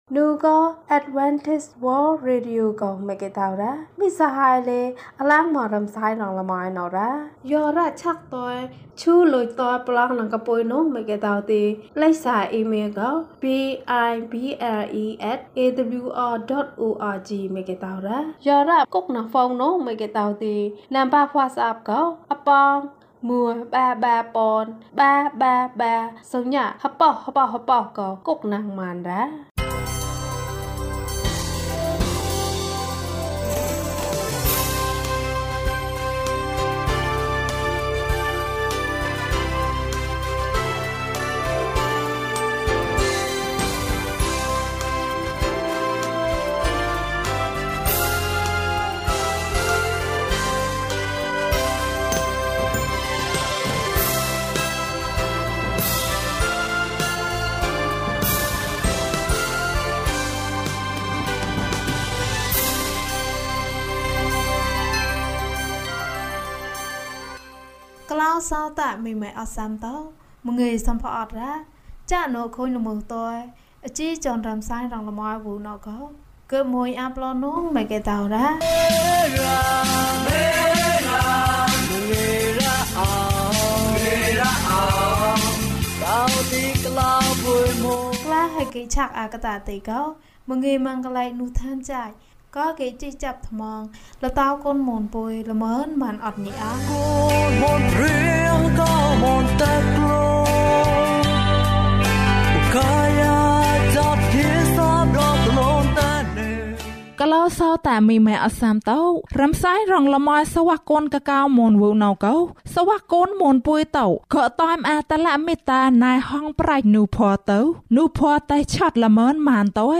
ဘုရားသခင် ငါ့ကို ကယ်တင်ပါ။ ကျန်းမာခြင်းအကြောင်းအရာ။ ဓမ္မသီချင်း။ တရားဒေသနာ။